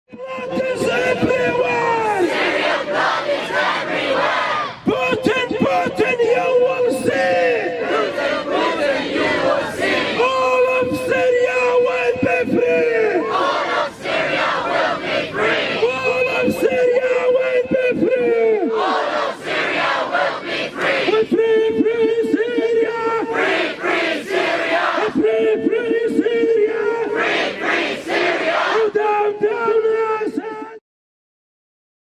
'Down down Russia'： Protesters in London condemn Russian offensive on Aleppo.mp3